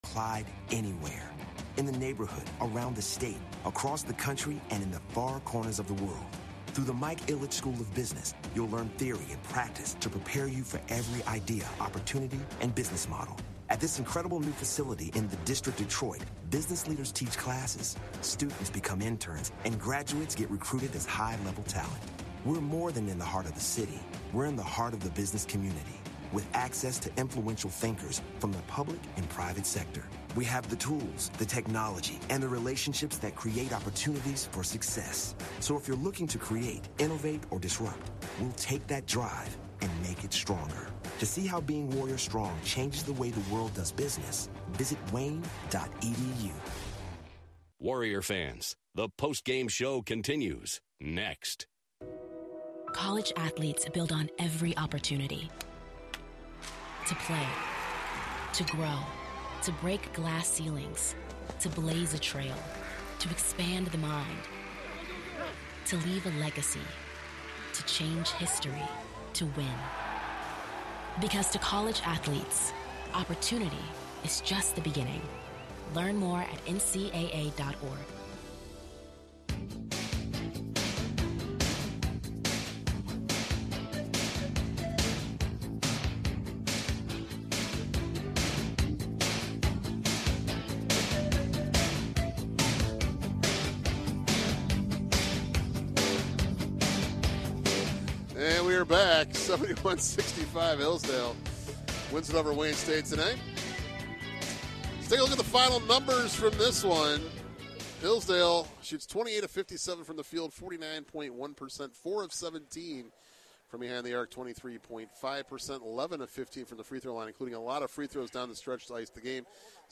Men's Basketball WDTK Broadcast vs. Hillsdale (Part 2)